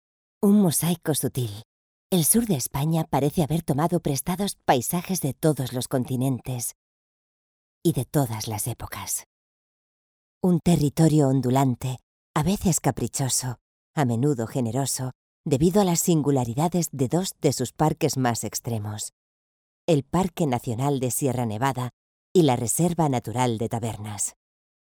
Voces para documentales en español